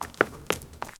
Seamless loop of realistic boot footsteps in 2/4 time, as if from my own feet. One step per beat, steady walking rhythm. Clean sound only — dull, earthy steps with no background noise.
two-footsteps-in-24-time-vl7vixix.wav